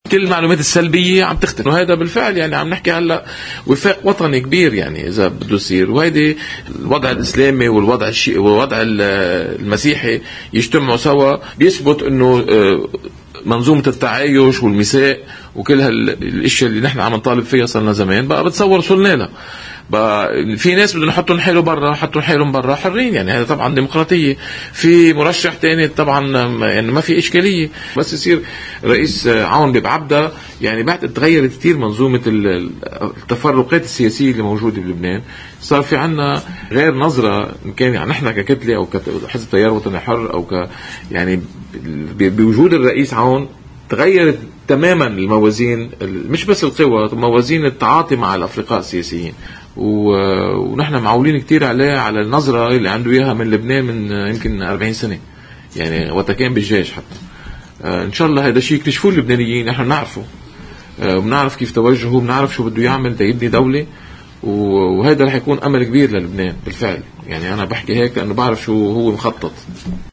إعتبر عضو تكتّل التغيير والإصلاح النائب وليد الخوري في حديث الى قناة الـ”OTV” أنّ العقبات تذلل شيئاً فشيئاً، وقال: